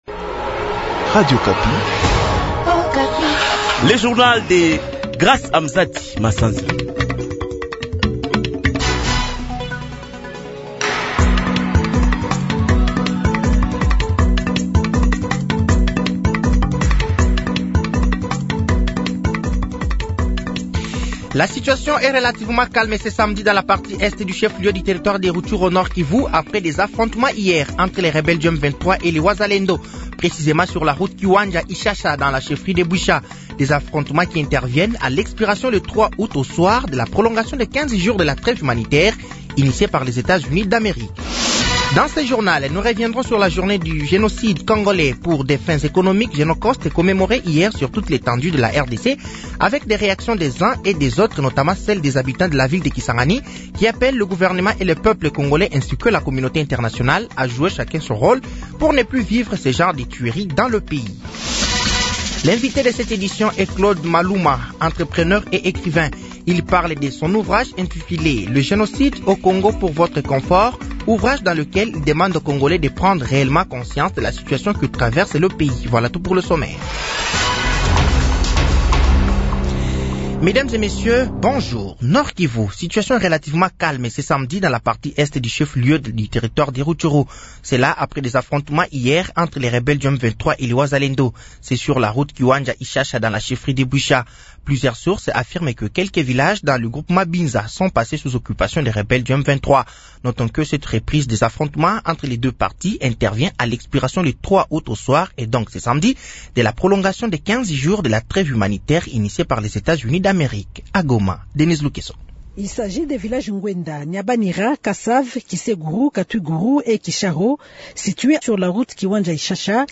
Journal français de 15h de ce samedi 03 août 2024